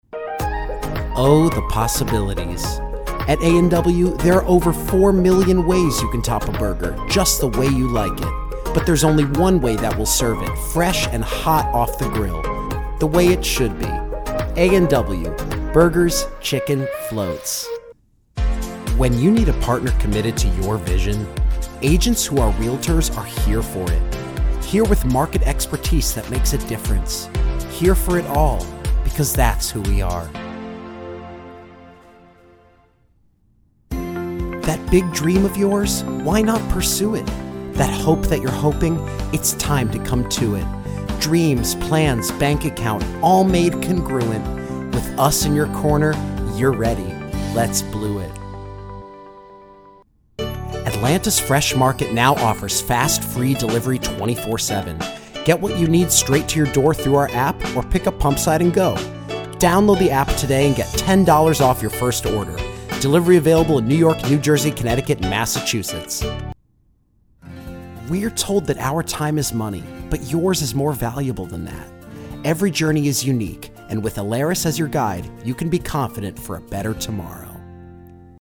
Voiceover Reel